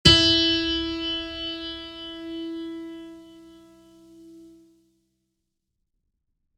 multiplayerpiano / sounds / HardAndToughPiano / e3.mp3
e3.mp3